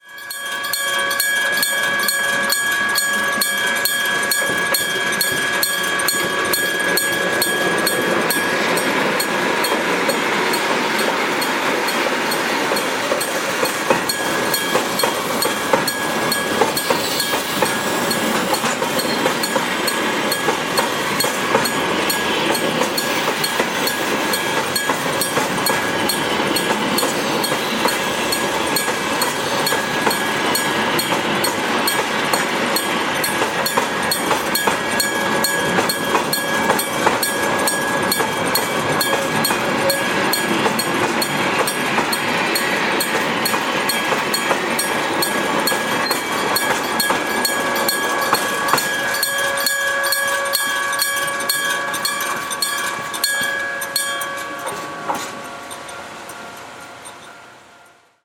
電鐘内のフライホイールの関係で警報灯が消えた後も余韻を残して数回鳴っている。
鉄製電鐘：ディンディンと澄んだ美しい音色。
2021.4　　　音色   三岐線鉄製電鐘式の大矢知５号踏切。
ディンディンと美しい音色。